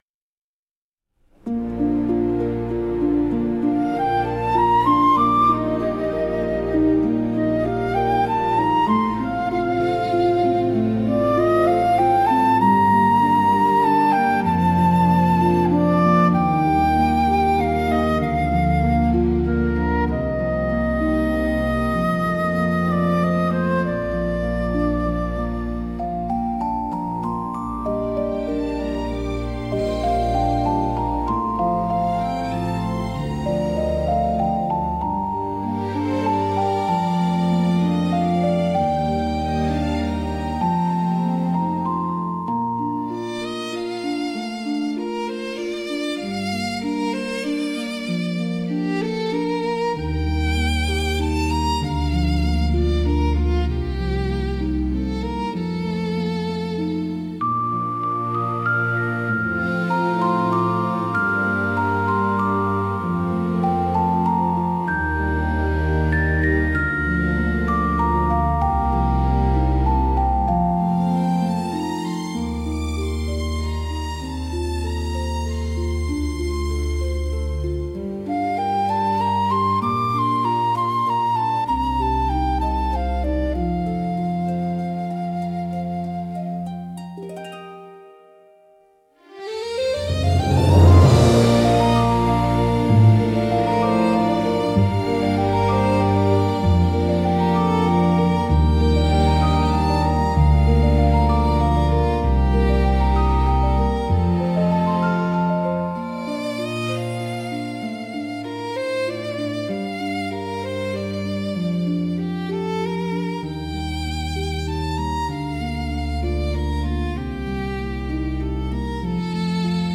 オリジナルの朝ジャンルは、しっとりと穏やかでありながら希望に満ちた明るい曲調が特徴です。
優しいメロディと柔らかなアレンジが一日の始まりを穏やかに包み込み、聴く人に前向きな気持ちをもたらします。
静かで清々しい空気感を演出しつつ、心に明るい希望や期待を芽生えさせる効果があります。